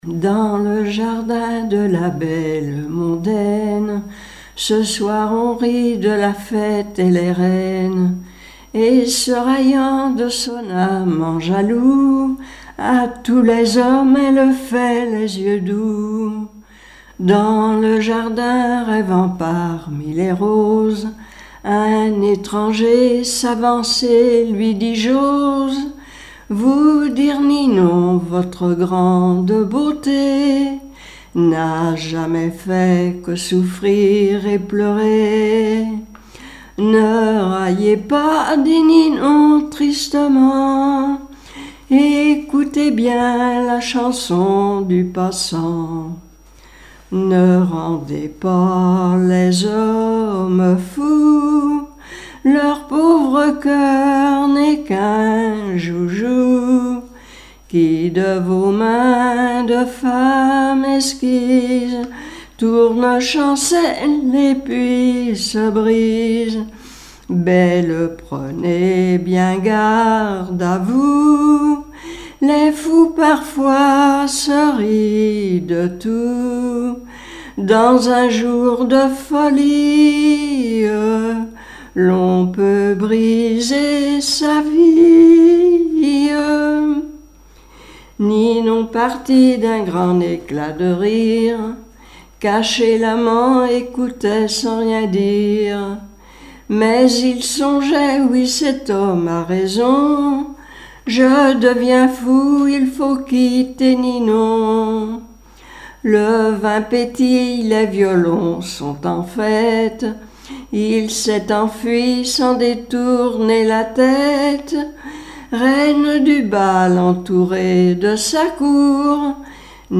strophique
Témoignages et chansons
Pièce musicale inédite